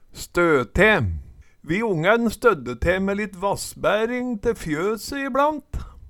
stø te - Numedalsmål (en-US)